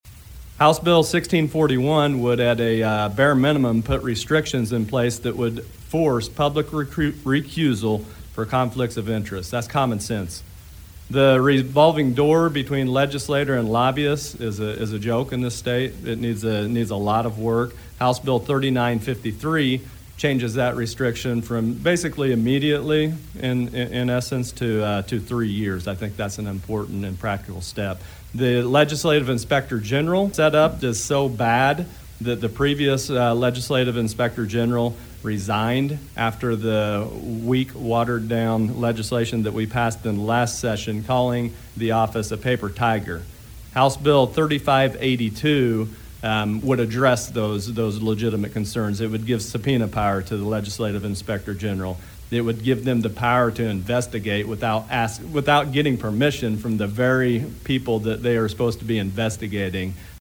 At a news conference on Thursday, the bills were discussed.  State Representative Blaine Wilhour lays out the different pieces of legislation and their intentions.